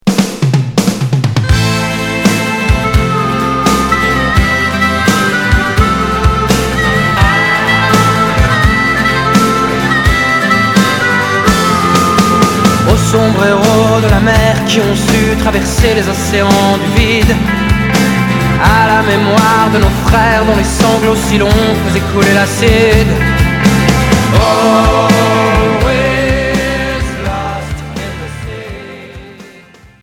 Rock Troisième 45t r etour à l'accueil